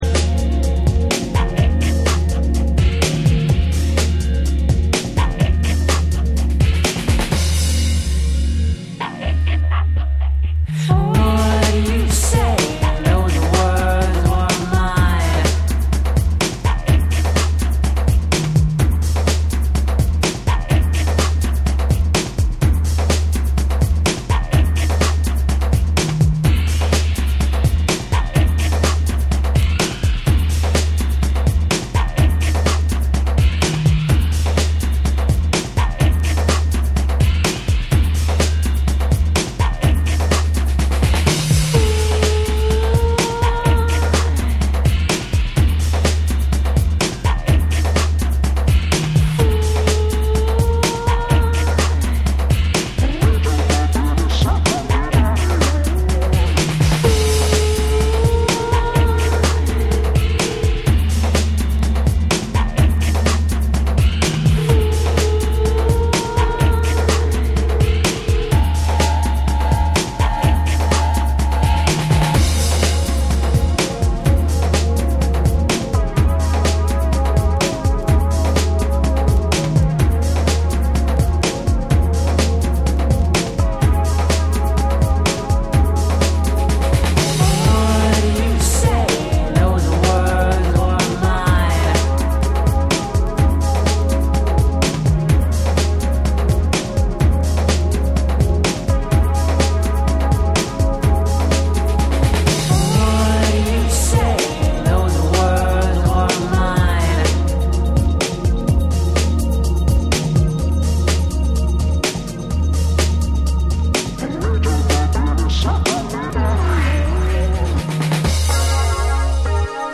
オリジナルのダークでムーディーなトリップ・ホップをよりエレクトロニックに、またはビート重視のアプローチで再構築！
BREAKBEATS